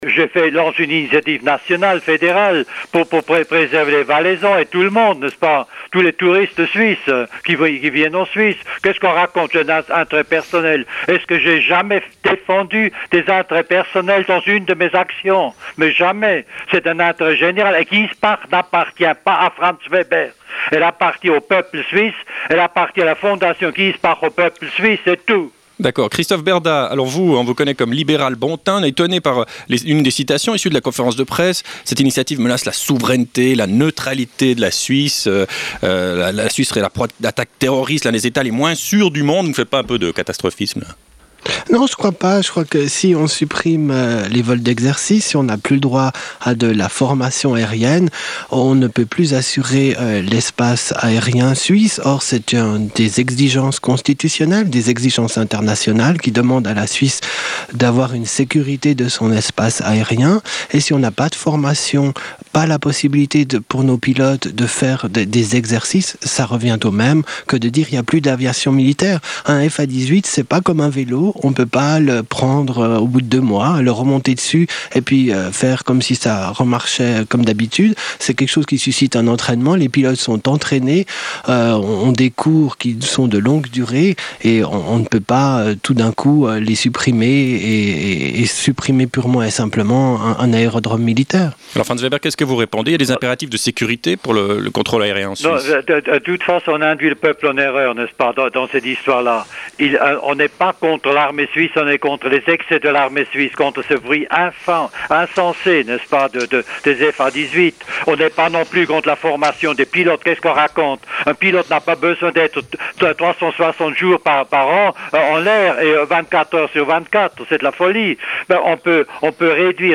Le débat